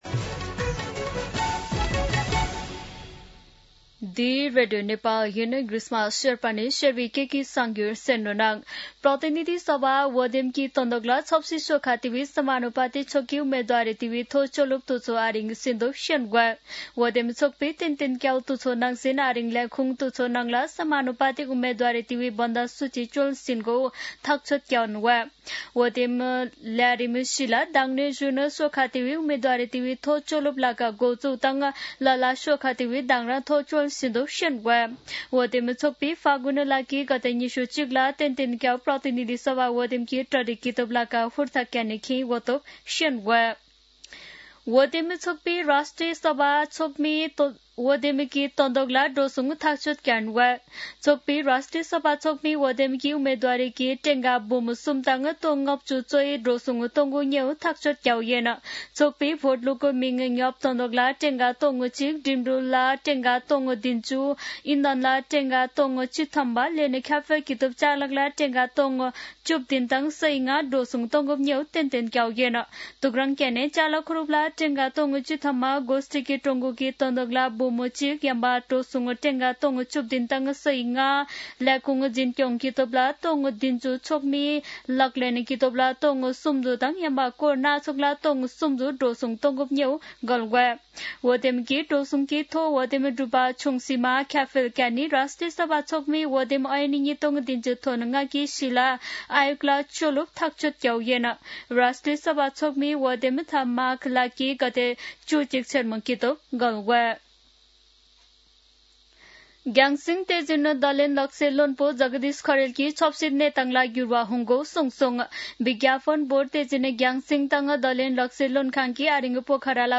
शेर्पा भाषाको समाचार : १४ पुष , २०८२
Sherpa-News-14.mp3